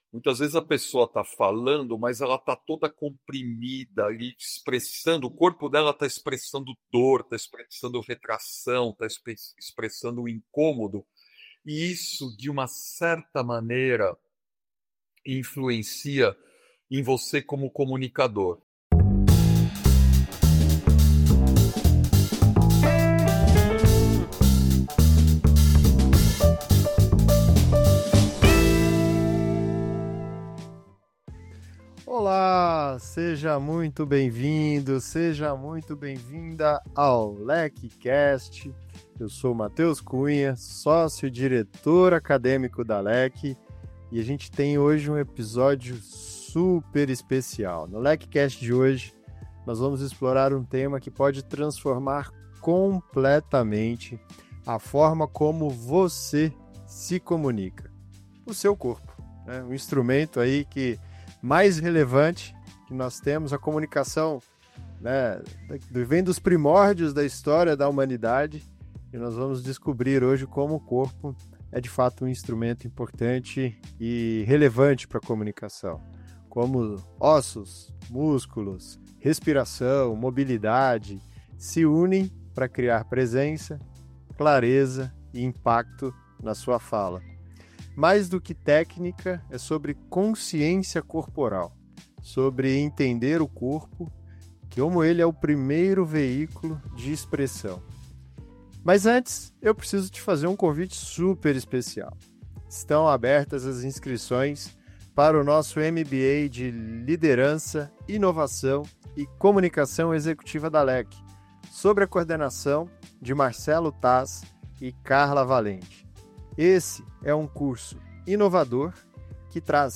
em um bate-papo inédito sobre como o corpo pode ser um veículo de escuta e expressão, explorando fundamentos da anatomia funcional e emocional aplicados à comunicação.